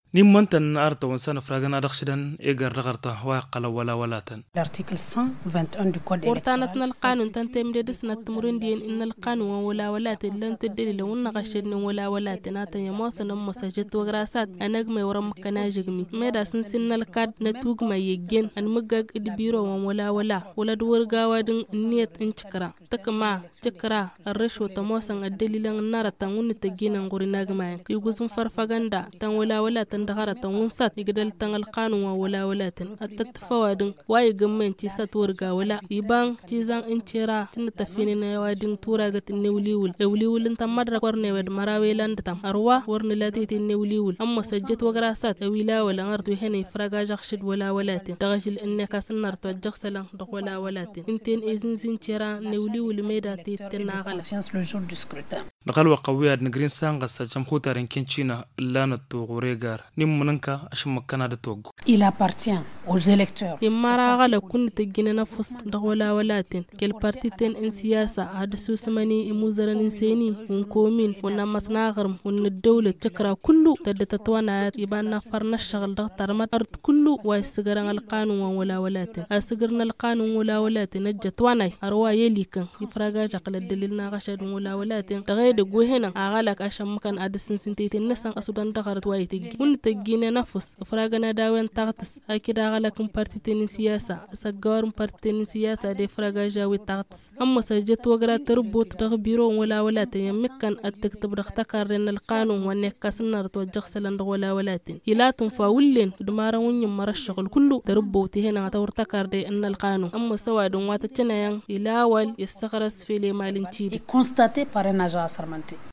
Le magazine en français